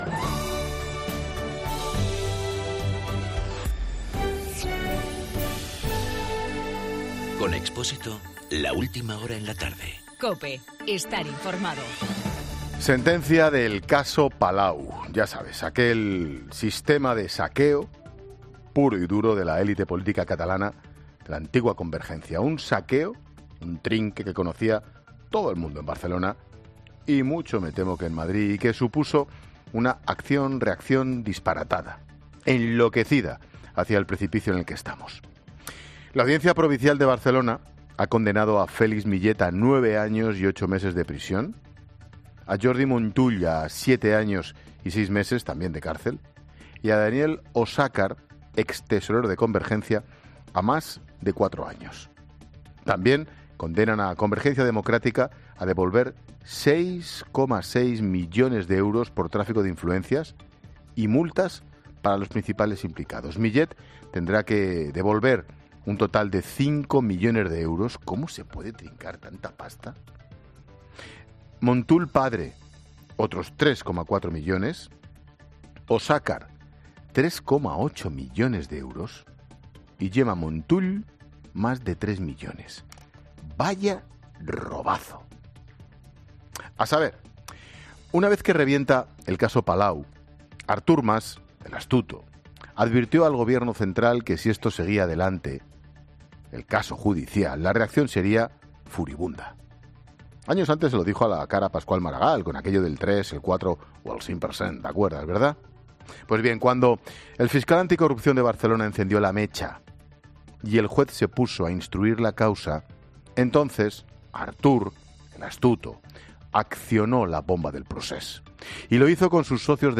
AUDIO: El comentario de Ángel Expósito sobre las sentencias del caso Palau y y los juicios de la trama Gürtel y del caso de los ERE.
Monólogo de Expósito